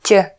tube, tune